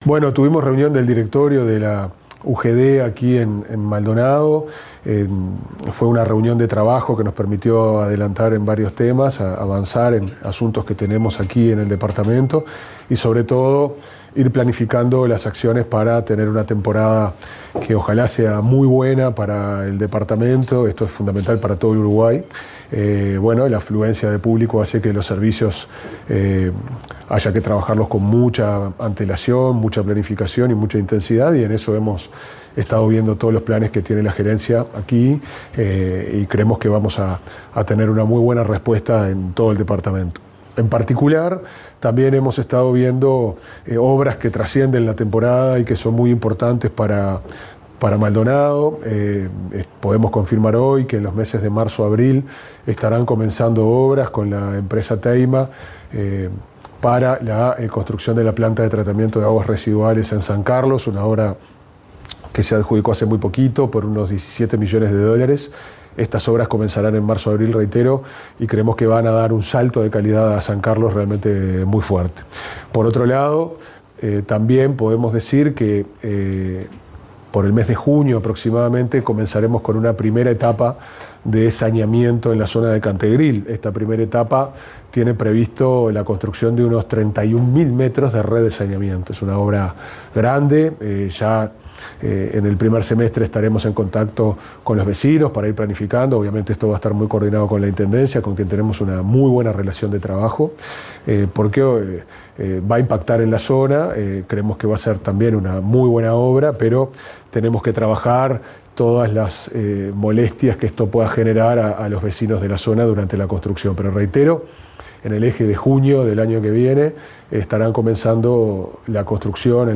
Declaraciones del presidente de OSE, Pablo Ferreri
Tras el anuncio de obras de saneamiento para el departamento de Maldonado, el presidente de OSE, Pablo Ferreri, dialogó con la prensa